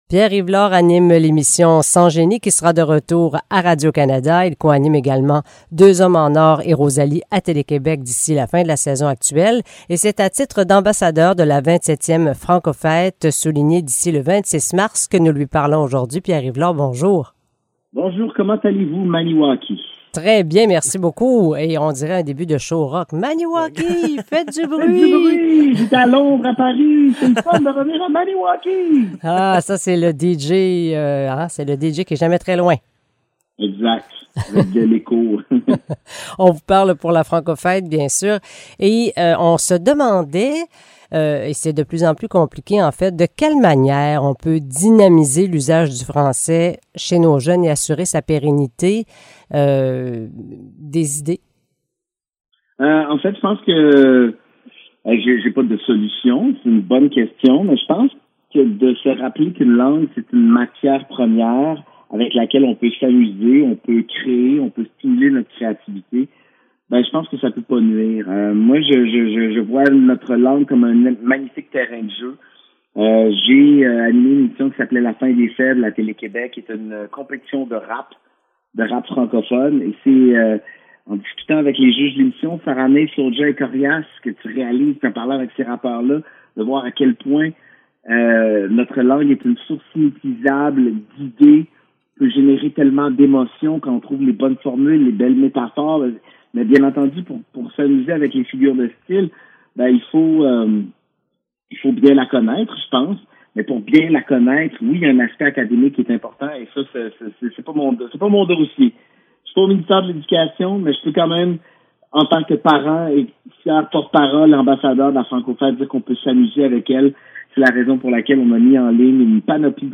Entrevue avec Pierre-Yves Lord
entrevue-avec-pierre-yves-lord.mp3